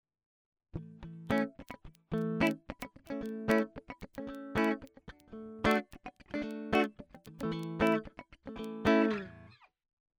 Knacksen, Knistern bei härterem Anschlag mit Plektrum
Grüß Euch, hab mir eine wunderschöne Godin 5th Avenue mit zwei P90 Pickups gegönnt. Der verstärkte Klang gefällt mir sehr gut, allerdings macht sich beim härterem Anschlag ein "Knistern bemerkbar".